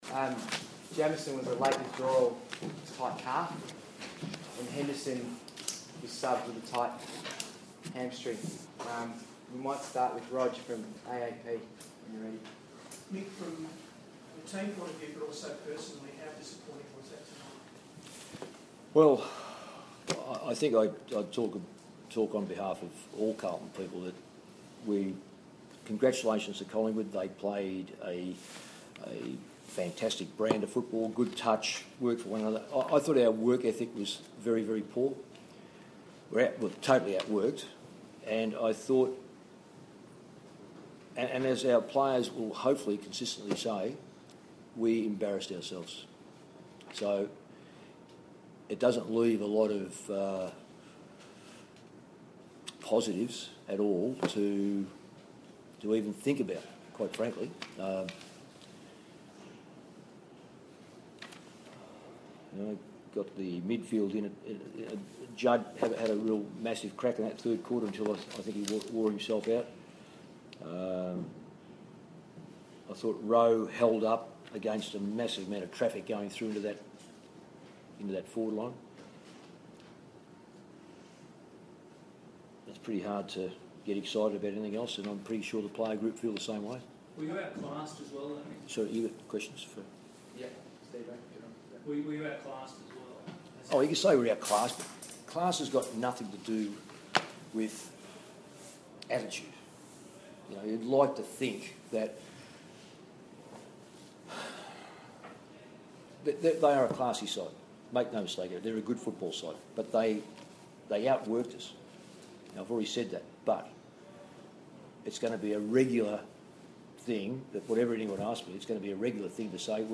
Round 5 post-match press conference
Coach Mick Malthouse speaks to the media after Carlton's 75-point loss to the Magpies.